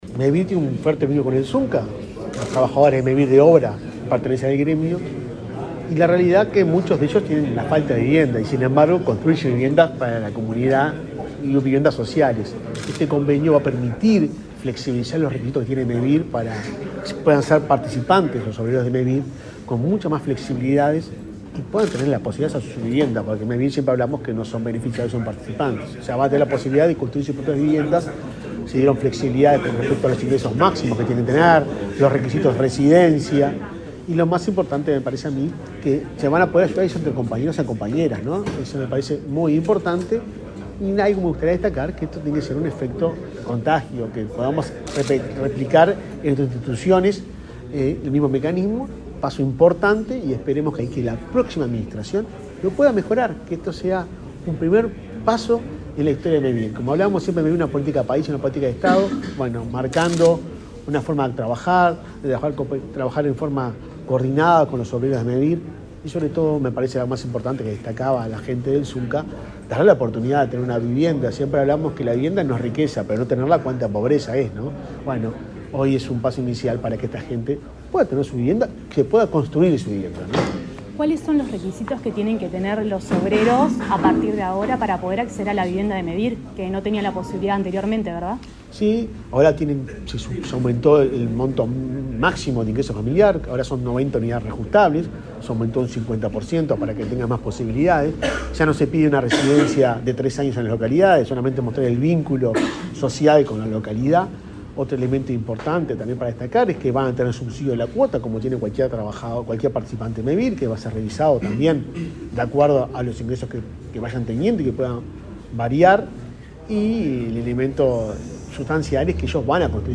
Declaraciones del presidente de Mevir, Juan Pablo Delgado
Luego dialogó con Comunicación Presidencial.